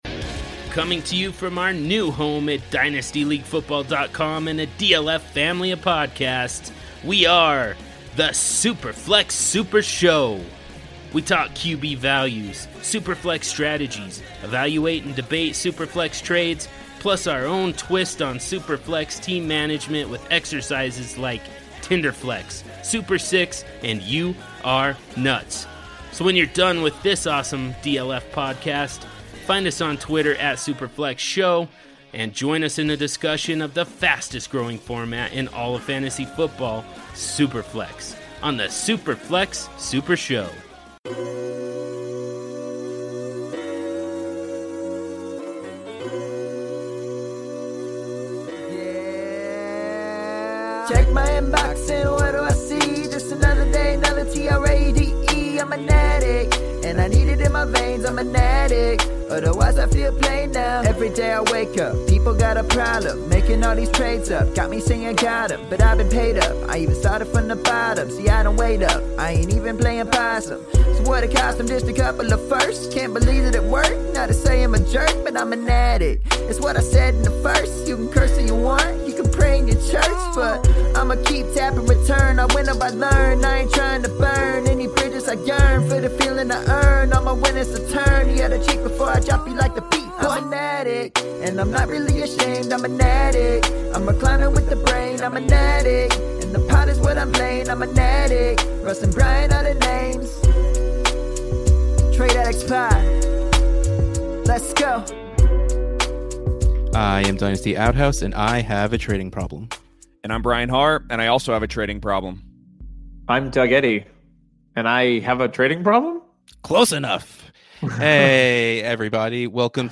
joins the pod and does some awesome imitations!!